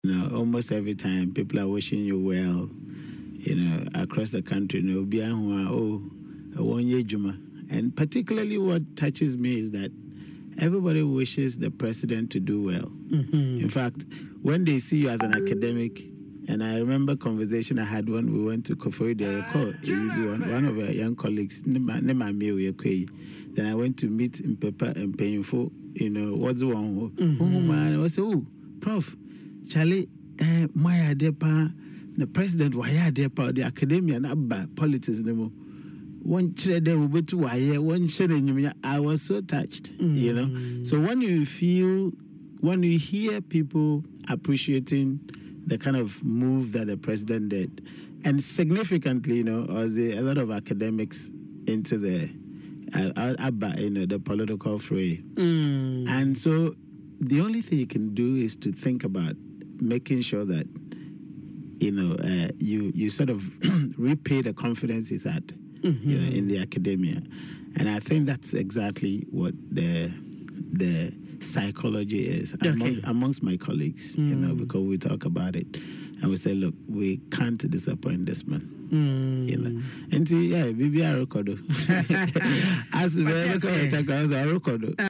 Prof. Mensah made this disclosure in an interview on Adom FM’s Dwaso Nsem, emphasizing that the support and goodwill messages he has received have been overwhelming.